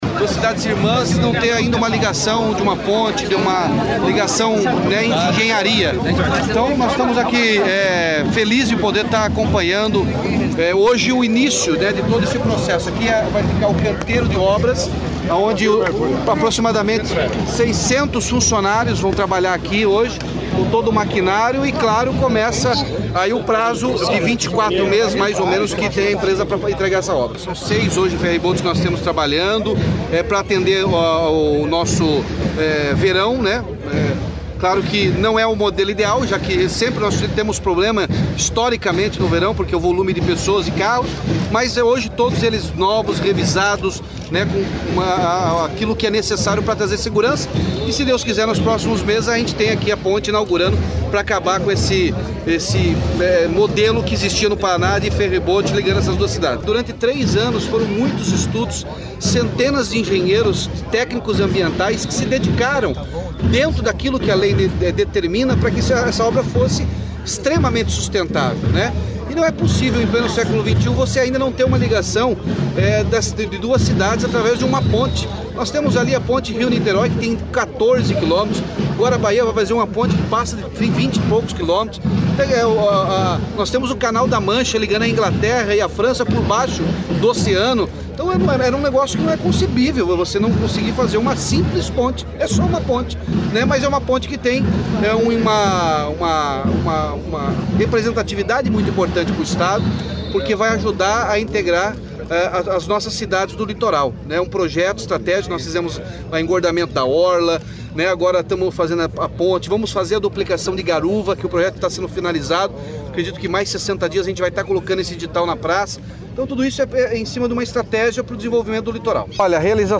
Sonora do governador Ratinho Junior sobre o início da obra da Ponte de Guaratuba